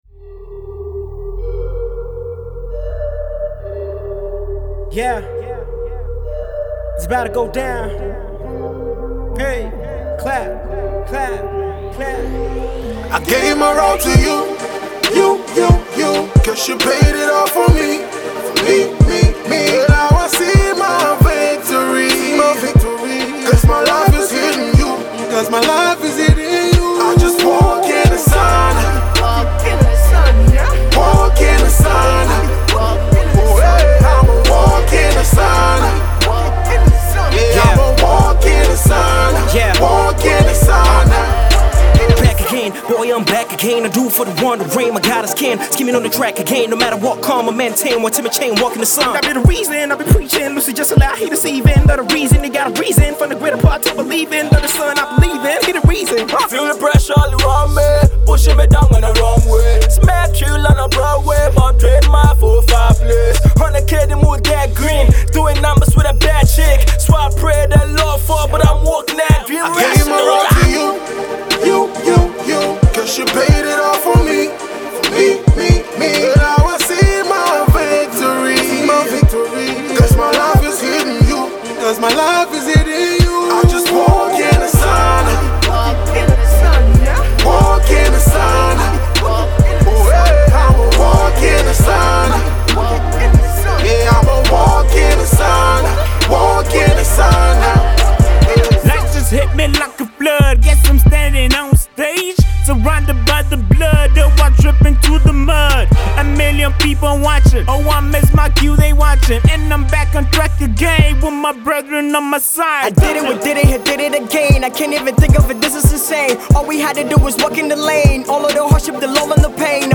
a group of 5 artists
Hip-Hop